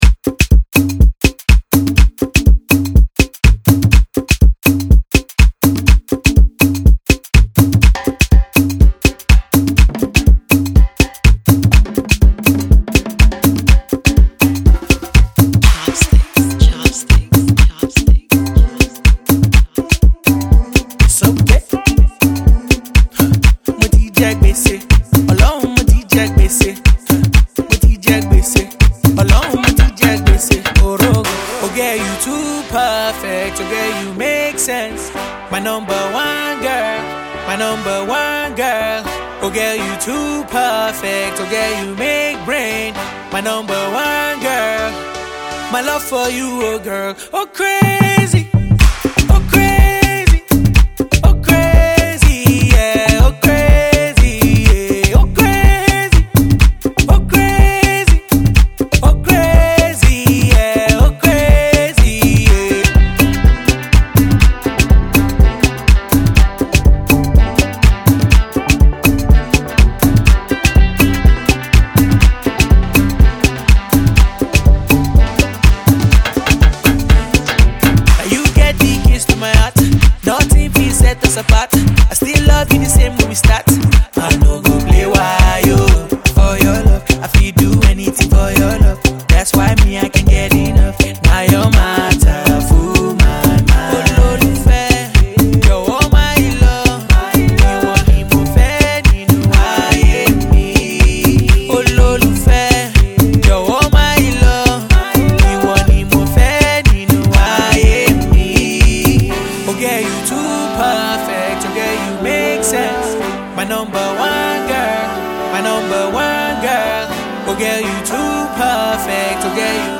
begins with an upbeat tempo.
catchy tune
summer jam